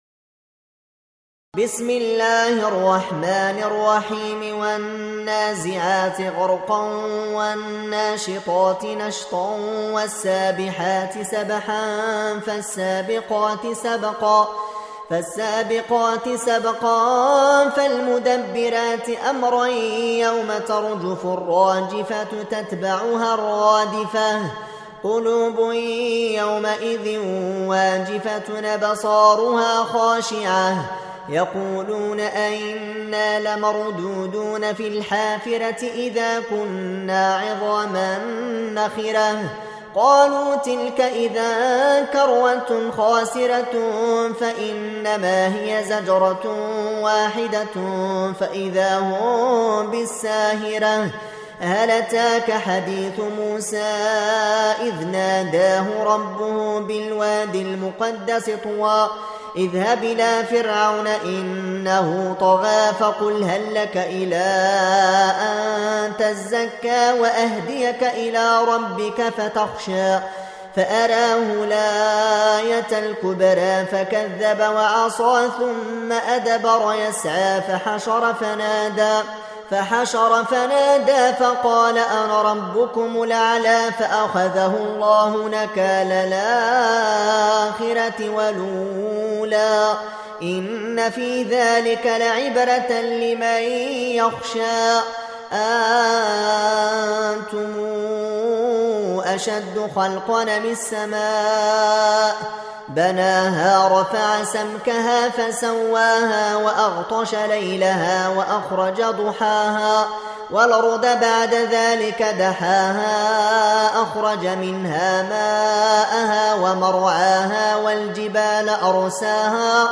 Audio Quran Tarteel Recitation
Surah Sequence تتابع السورة Download Surah حمّل السورة Reciting Murattalah Audio for 79. Surah An-Nazi'�t سورة النازعات N.B *Surah Includes Al-Basmalah Reciters Sequents تتابع التلاوات Reciters Repeats تكرار التلاوات